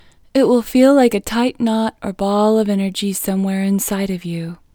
LOCATE IN English Female 28